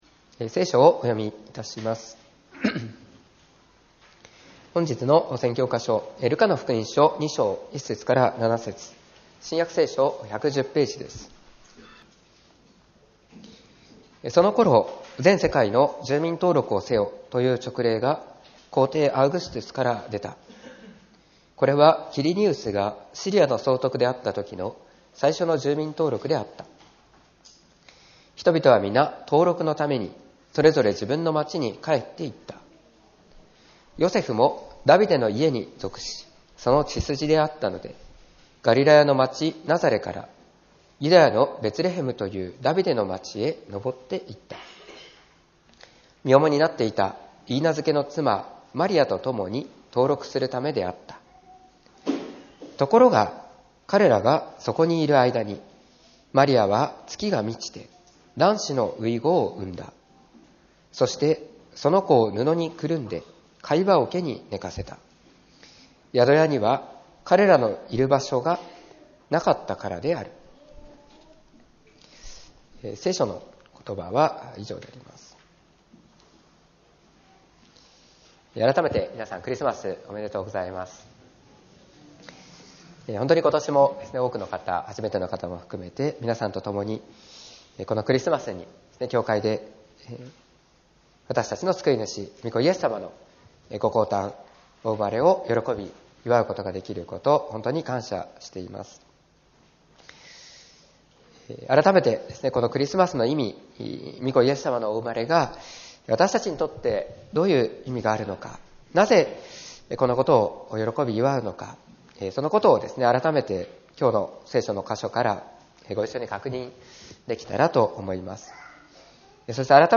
2024年12月22日(クリスマス主日) 礼拝説教「救い主の誕生」